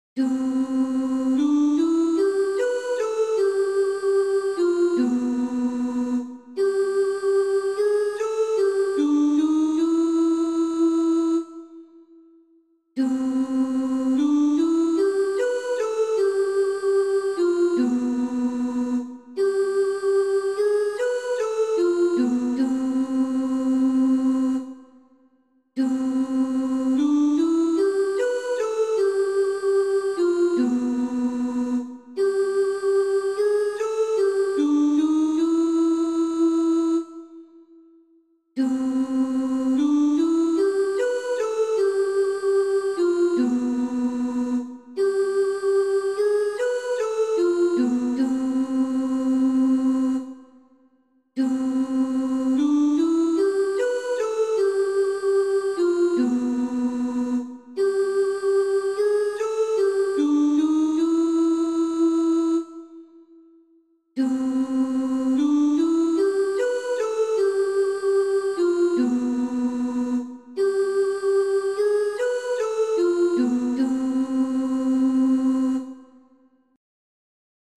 FCCV2023-A-simple-melody-2-solo.mp3